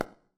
surface_felt4.mp3